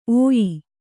♪ ōyi